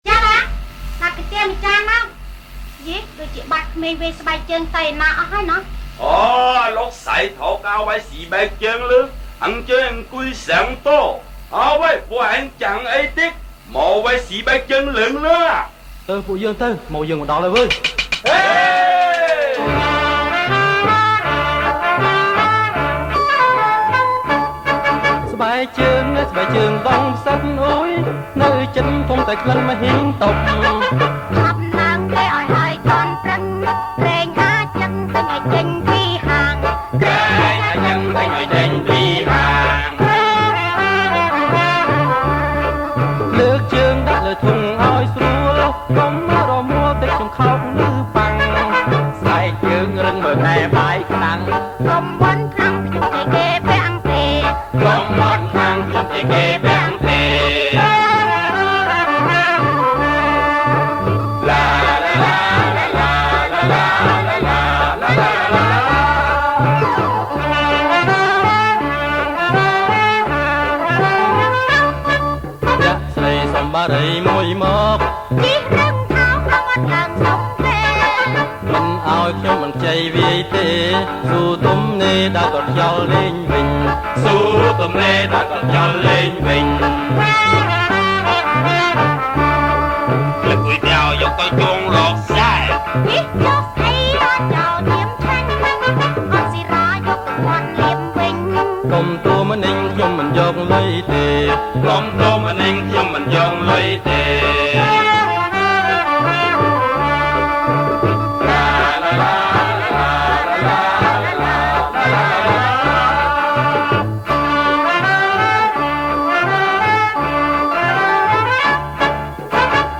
ប្រគំជាចង្វាក់ តាលុង